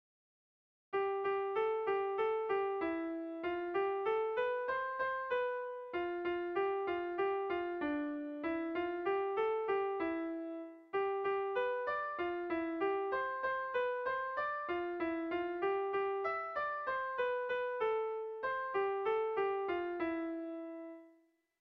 Doinu polita.
Zortziko txikia (hg) / Lau puntuko txikia (ip)